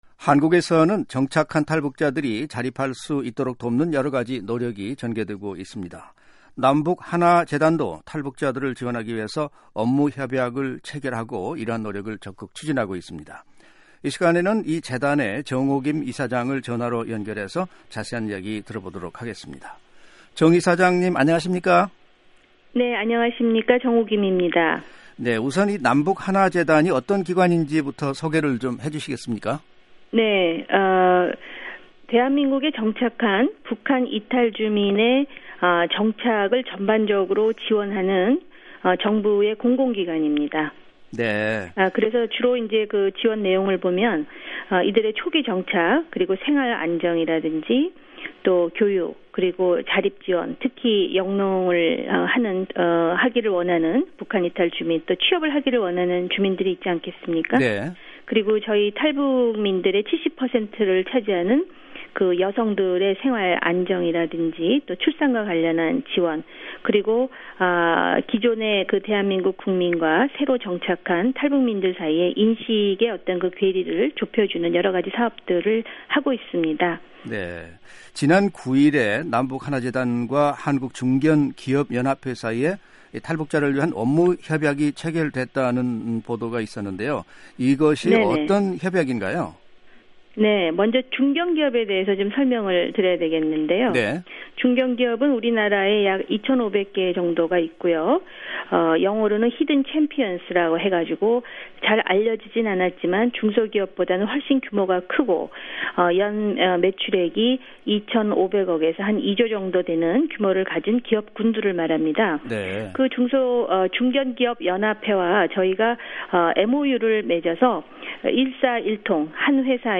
[인터뷰 오디오 듣기] 남북하나재단 정옥임 이사장